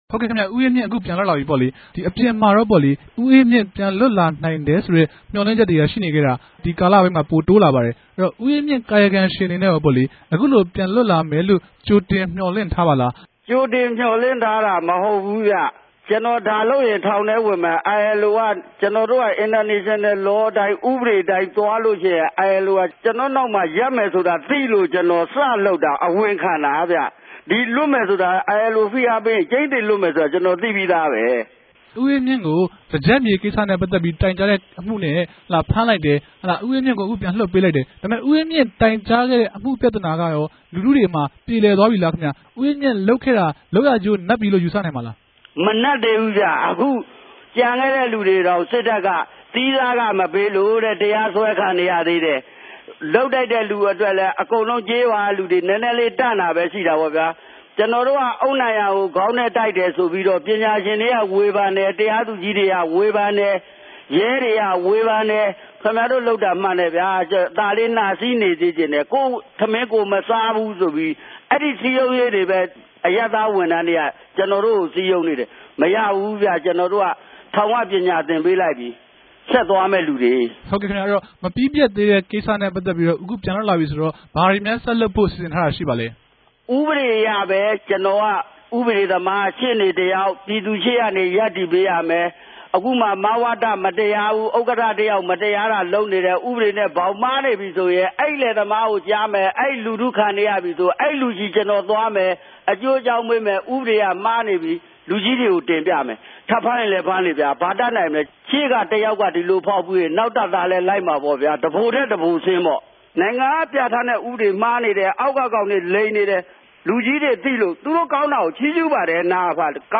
ဆက်သြယ် မေးူမန်းထားတာကို ၂ ပိုင်းခြဲ တင်ဆက်ထားပၝတယ်။